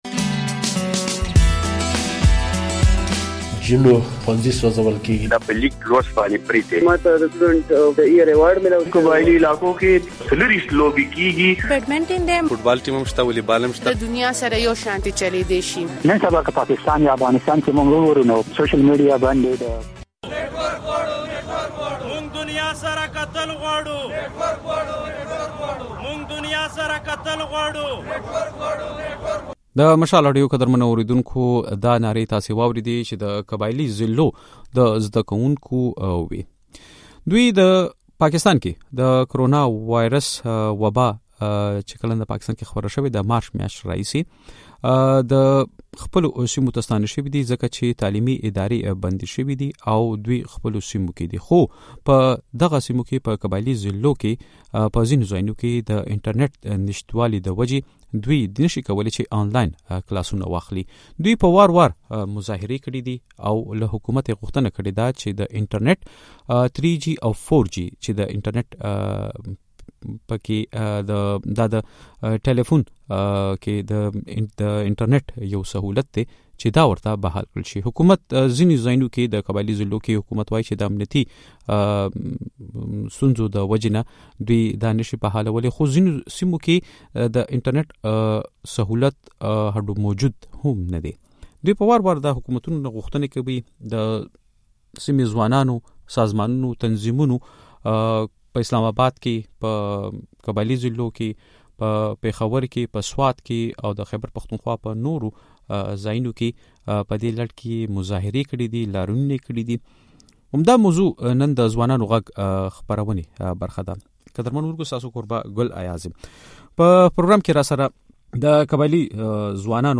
پر همدې موضوع مو د "ځوانانو غږ" خپرونه کې بحث کړی دی.